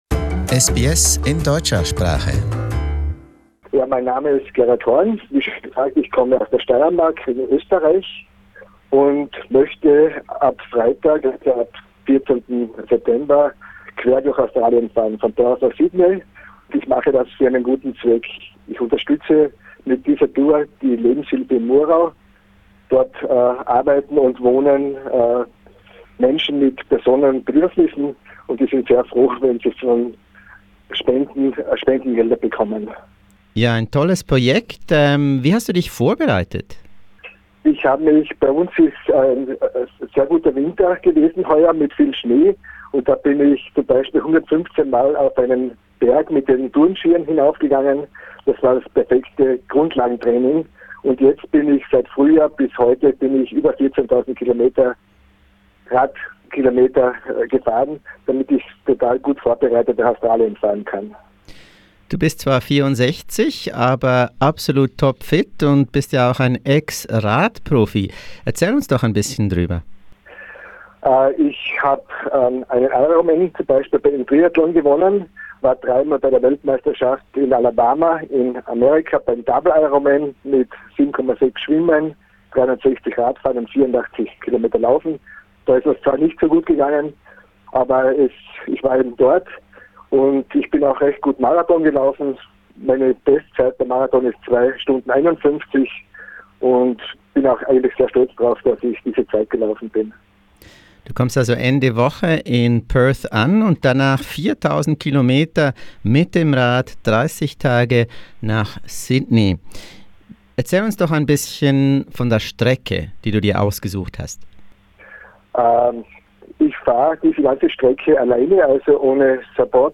Das sind 4,000 Kilometer in 30 Tagen! Wir sprachen mit ihm, kurz vor seinem Abflug in München.